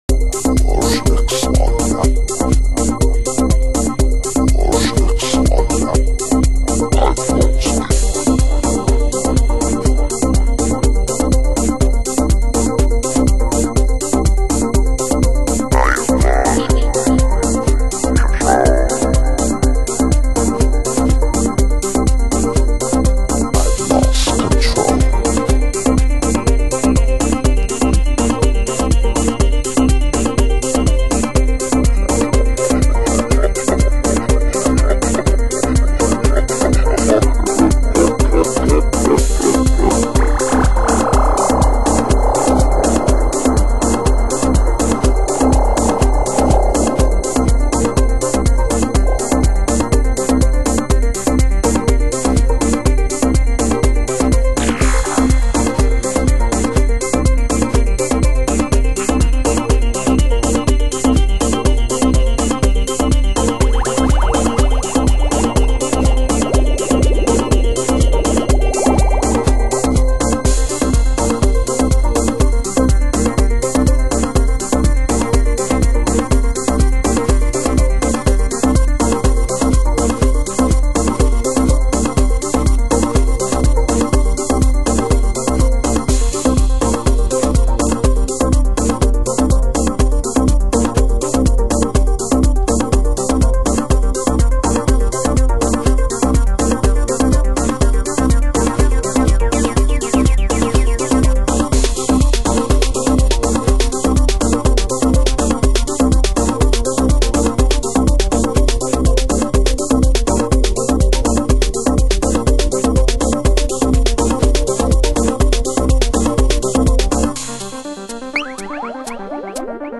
HOUSE MUSIC USED ANALOG ONLINE SHOP